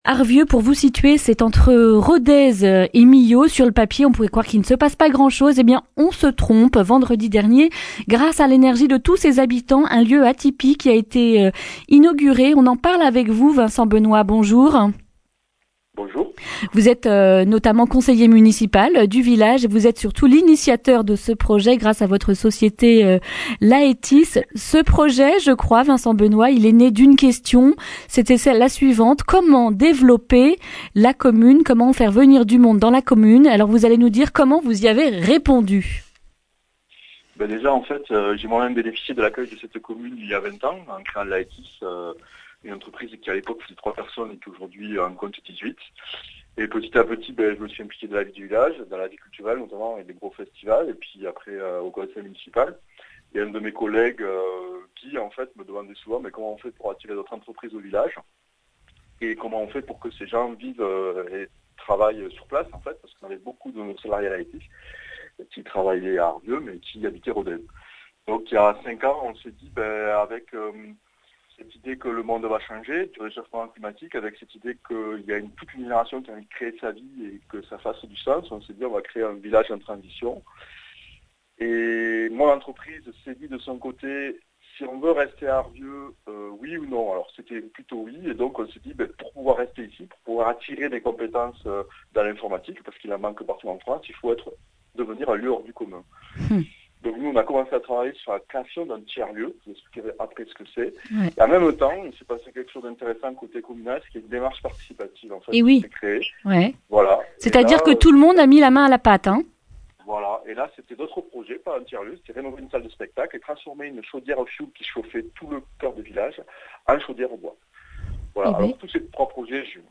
lundi 9 septembre 2019 Le grand entretien Durée 10 min